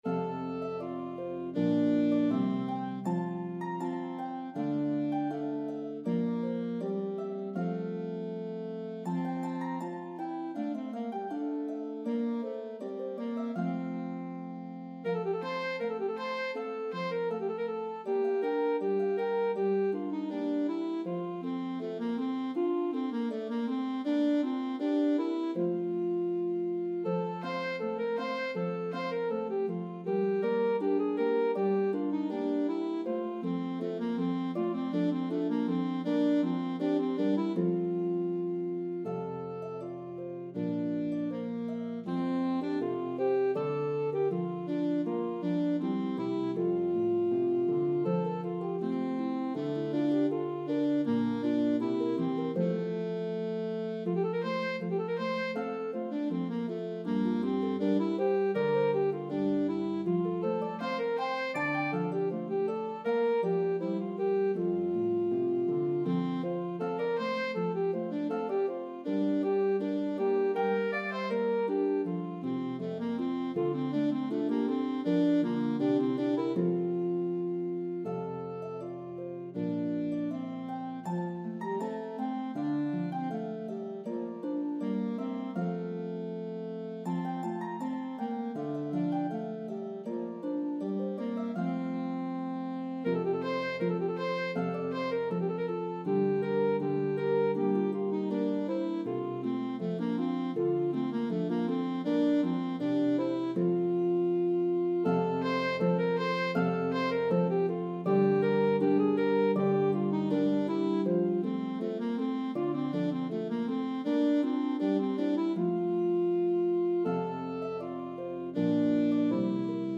Renaissance melodies